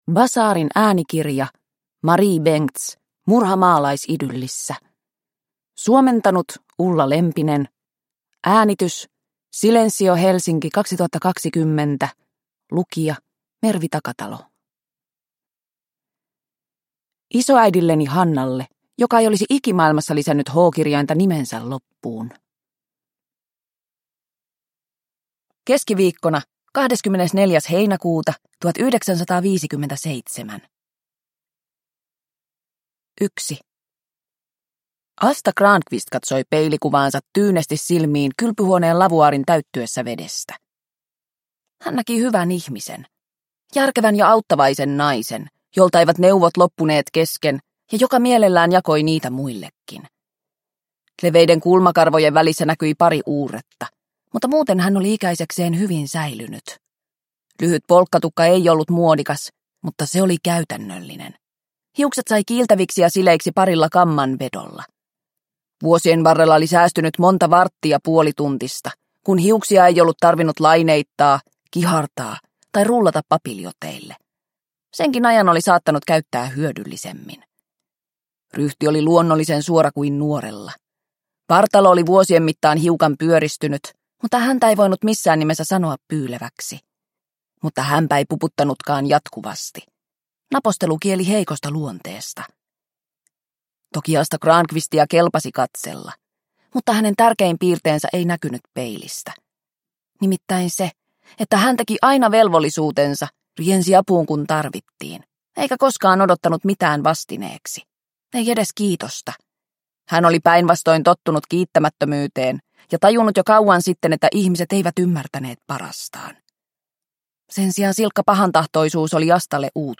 Murha maalaisidyllissä – Ljudbok – Laddas ner